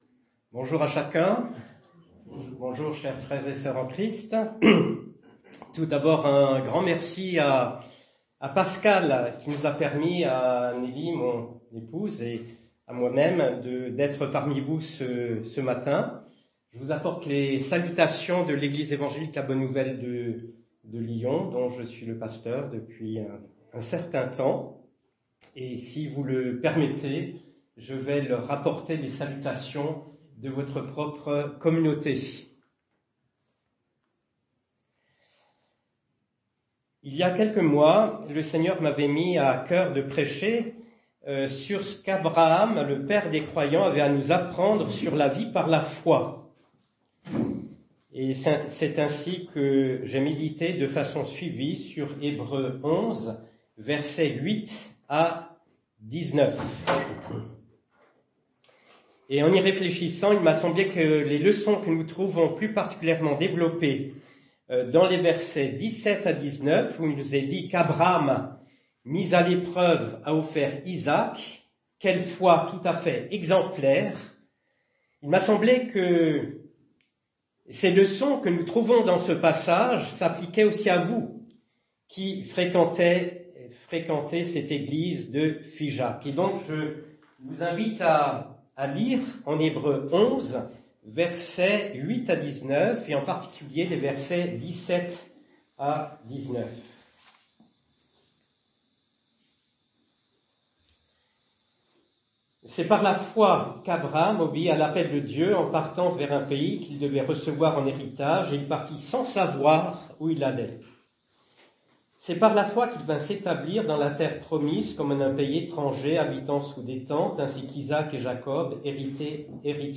Culte du dimanche 28 juillet 2024 - EPEF